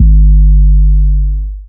DDW3 808 7.wav